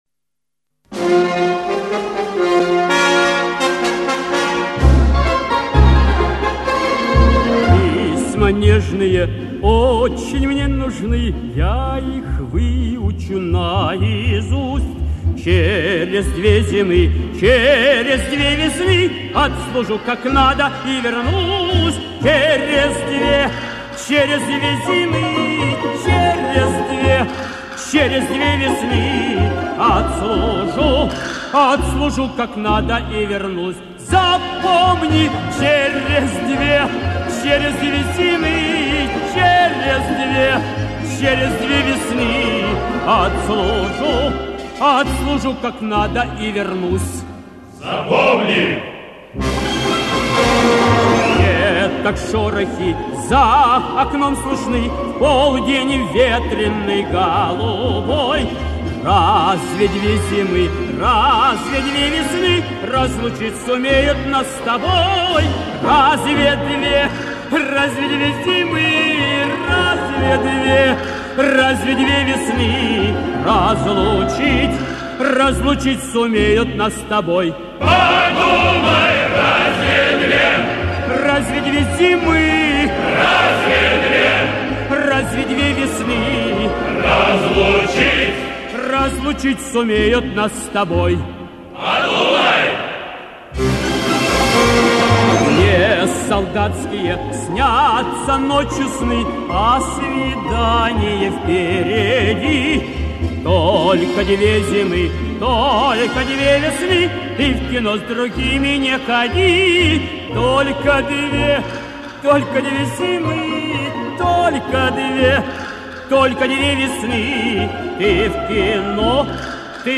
Замена на более качественную запись.
солист